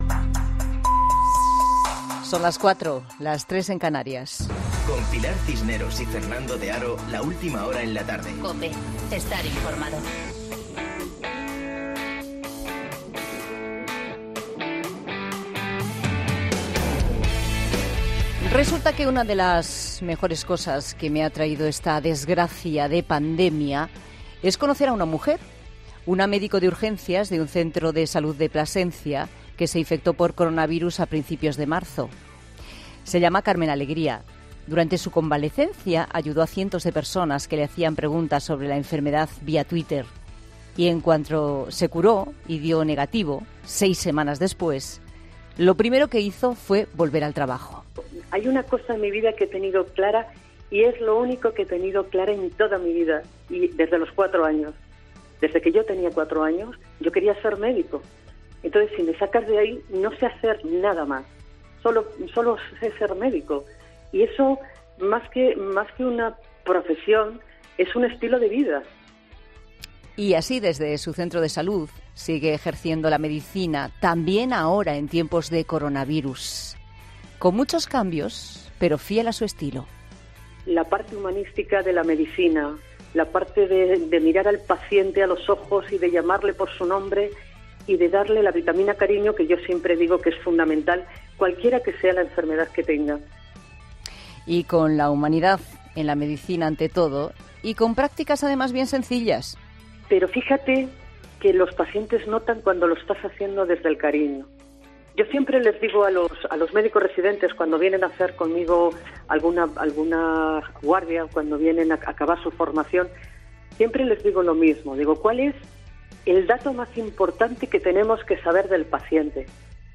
Monólogo de Pilar Cisneros